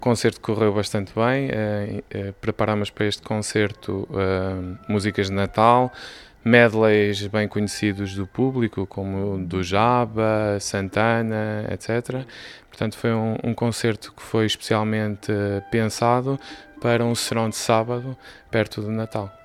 ntrevistas